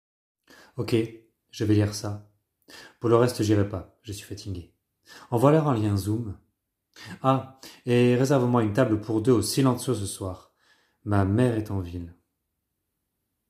Doublage marionnette